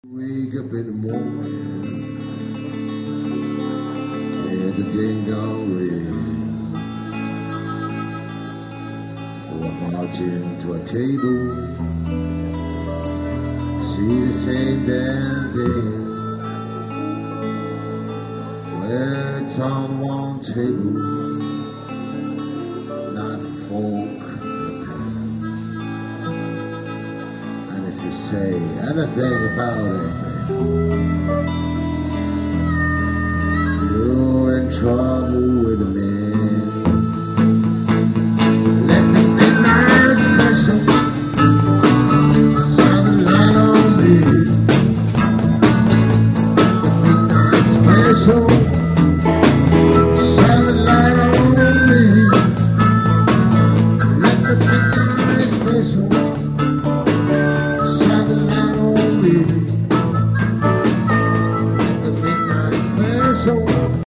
harmonicas
Des extraits, (30 secondes environ) du concert enregistré le 3 Mars 2000
au Relais de la Reine Margot (Longvic, Côte d'or) :